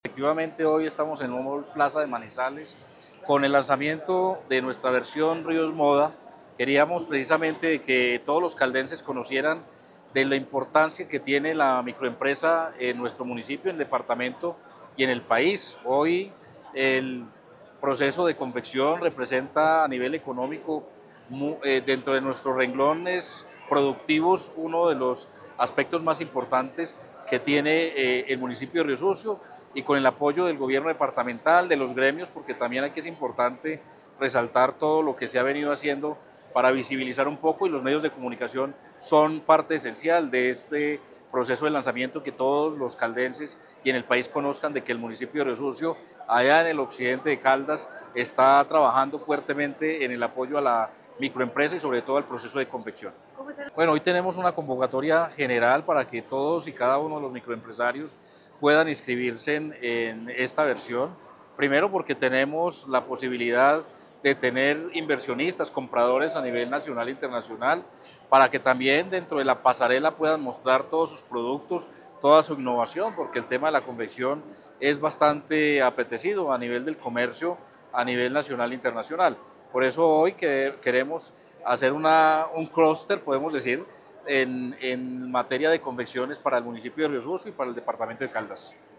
Abel David Jaramillo Largo, alcalde de Riosucio